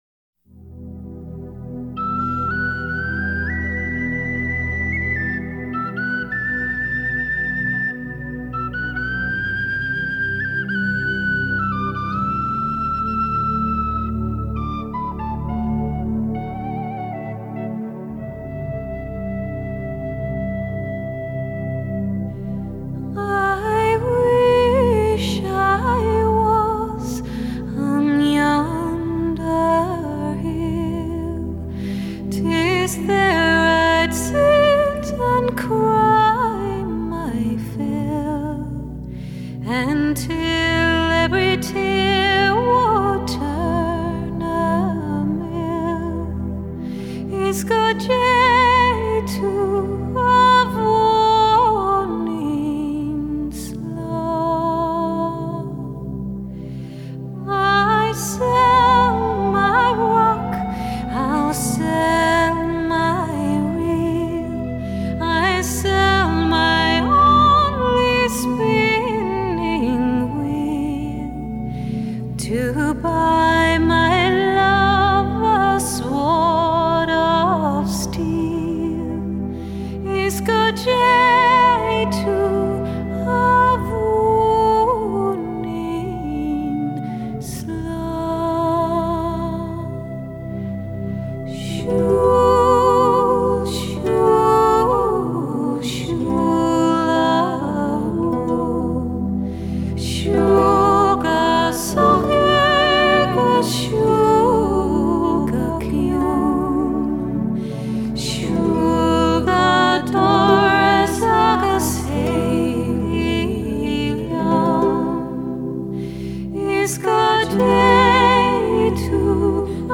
以不同领域的女声融合传统爱尔兰音乐
整张专辑融合了各种乐风，却仍以凯尔特传统音乐巧妙贯穿其中